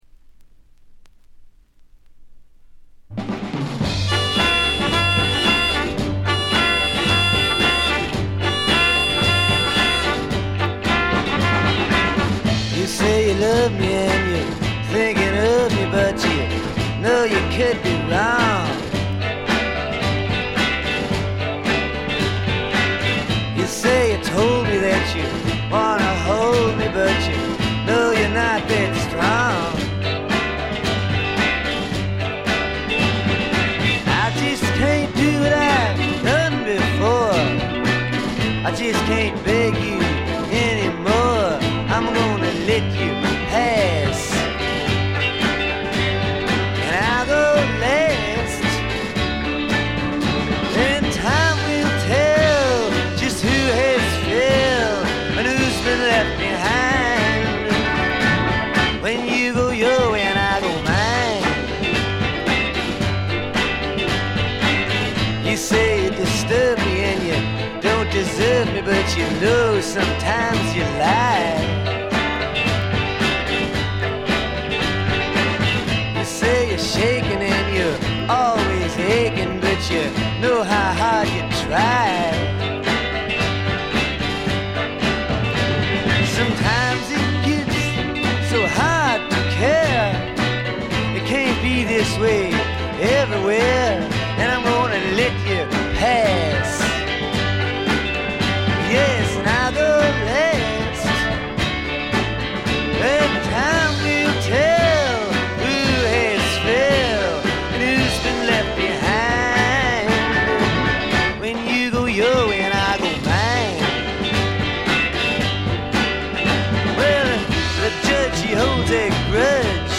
試聴曲は現品からの取り込み音源です。
vocals, guitar, harmonica, piano